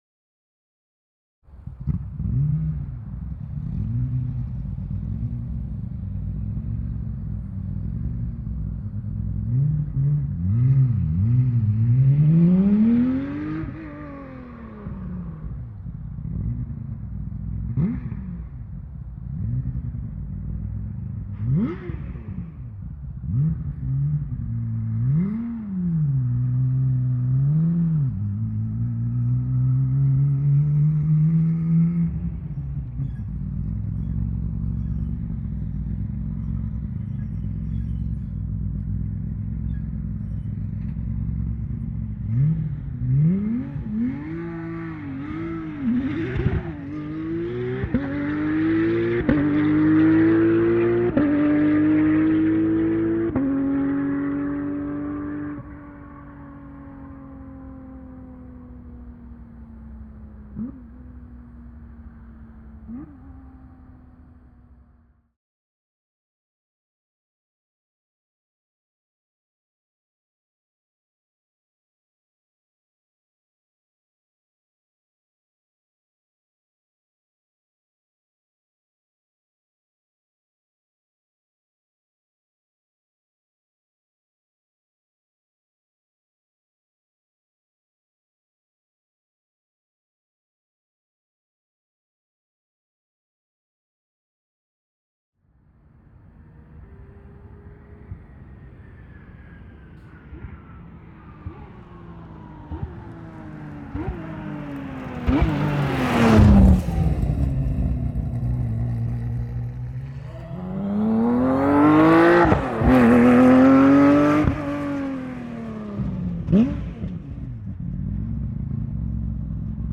Pole Position - Porsche 997 Cup 2012